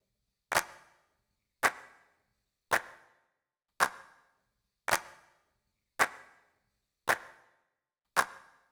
13 Clap.wav